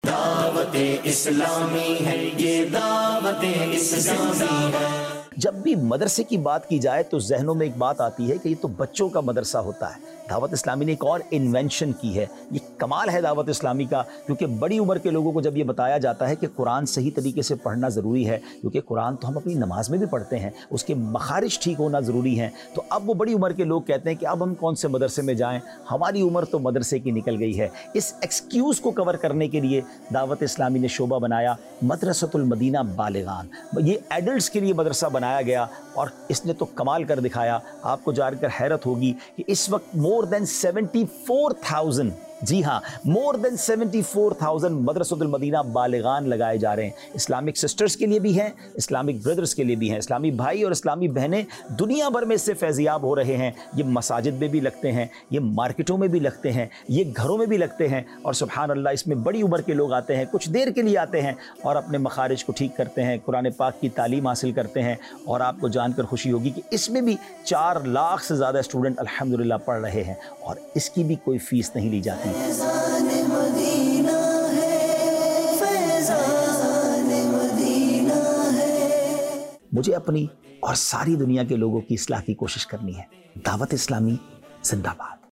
Documentary 2025